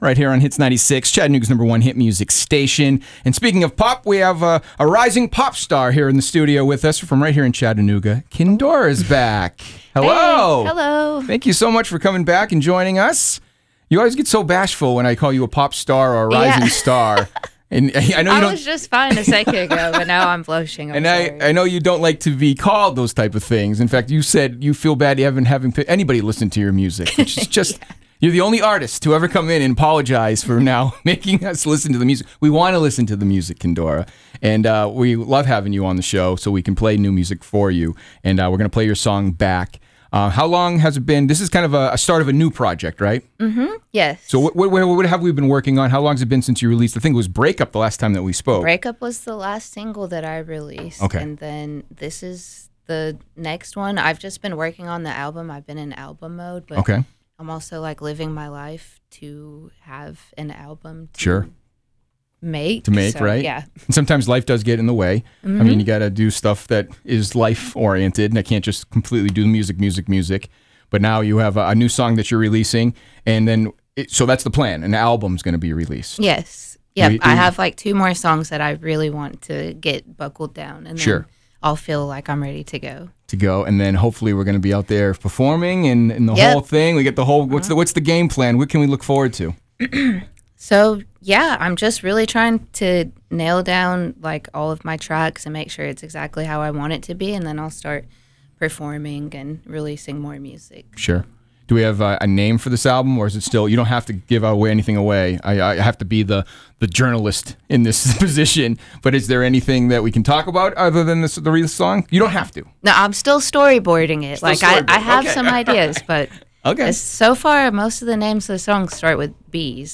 Known for her pop sound and captivating stage presence
catchy hook